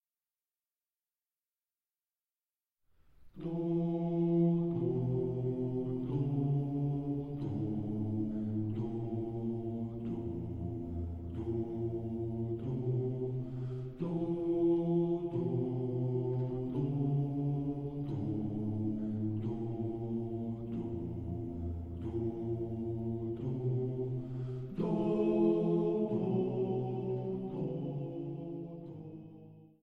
Truhenorgel
Große Orgel (Improvisationen)